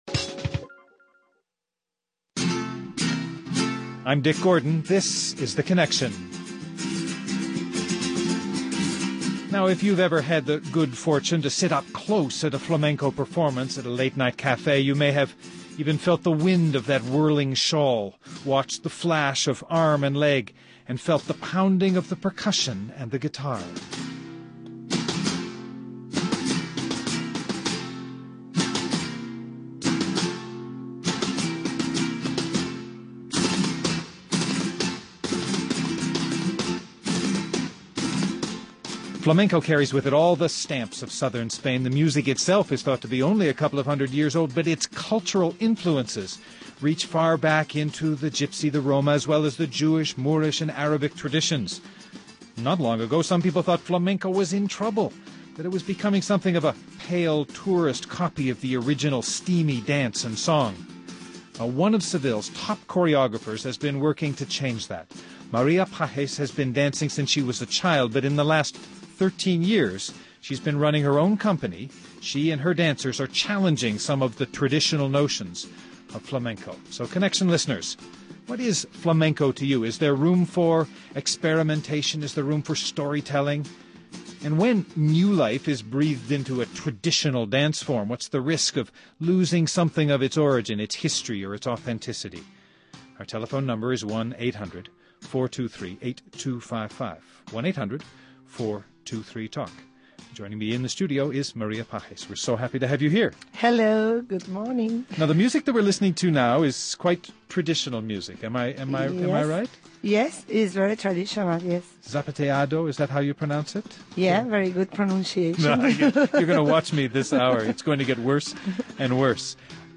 Guests: Maria Pages, dancer and choreographer